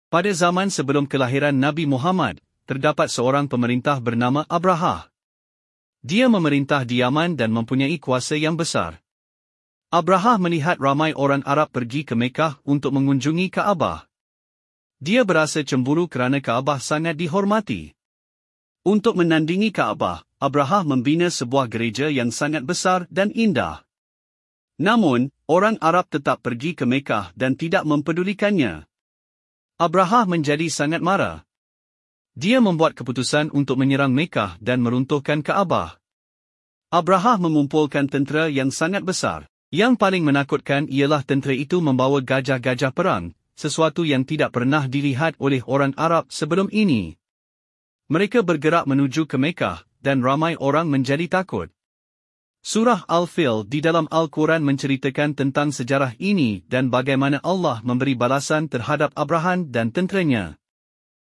narration.mp3